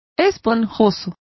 Complete with pronunciation of the translation of spongiest.